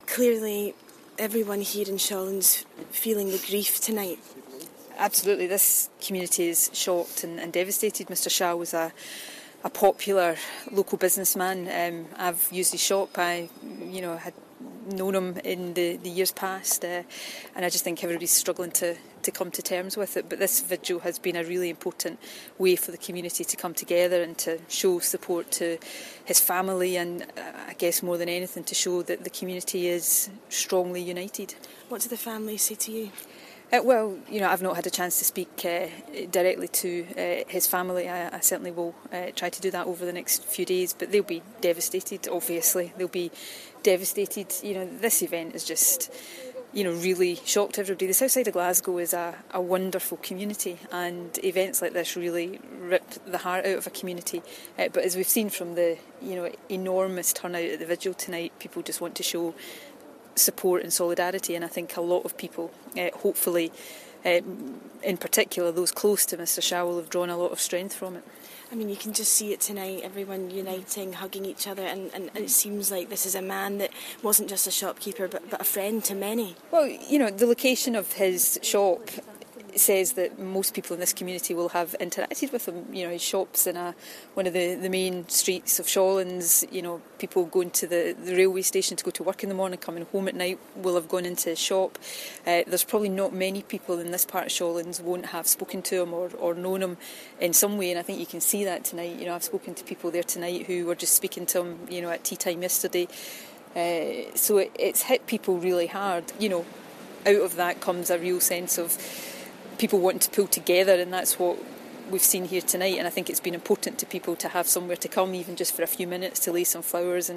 First Minister Nicola Sturgeon talks to our reporter